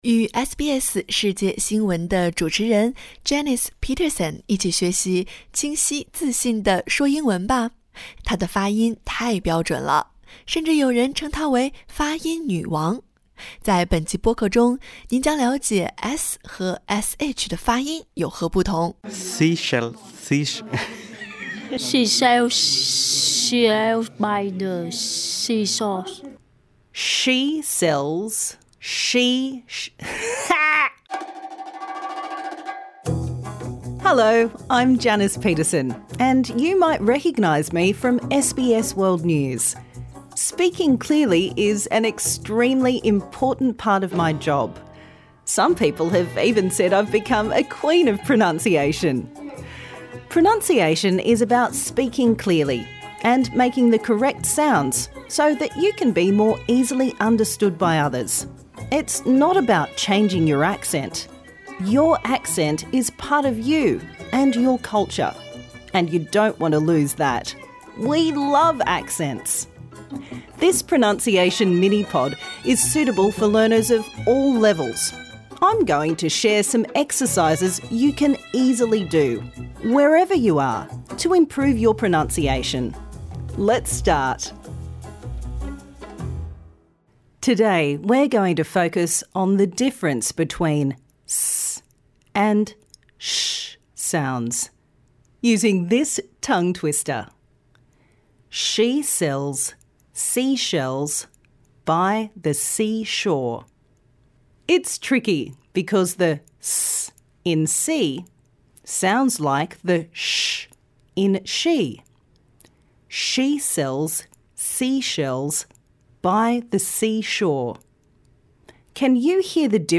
Minimal Pairs: /ʃ/ she shells ship sheet shoot shave /s/ sea sells sip seat suit save